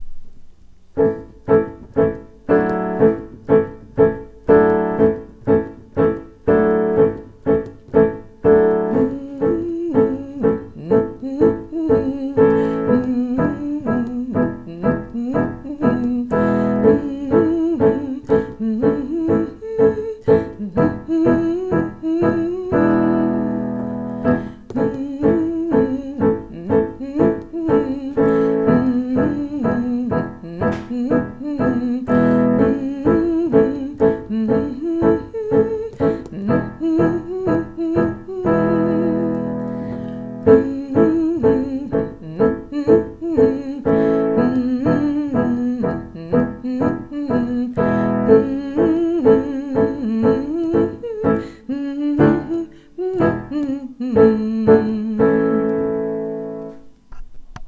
Shake_piano_instrumentaal.wav